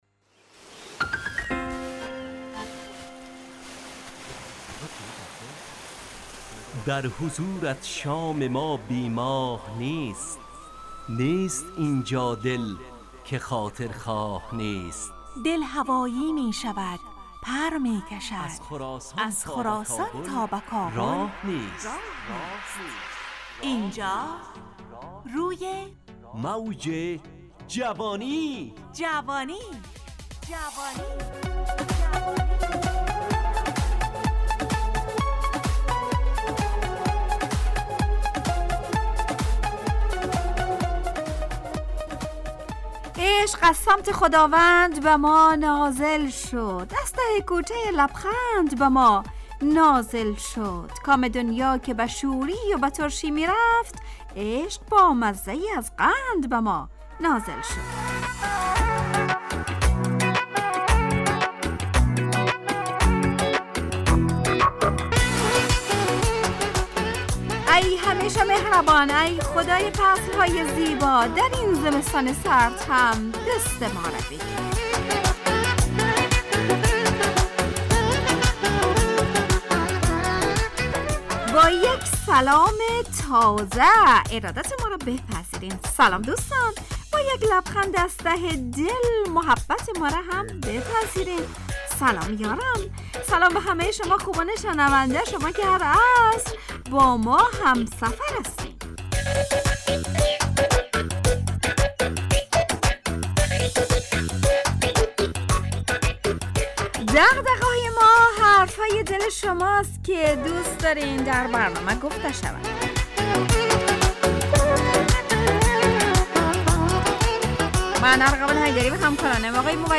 همراه با ترانه و موسیقی مدت برنامه 70 دقیقه . بحث محوری این هفته (دغدغه)
برنامه ای عصرانه و شاد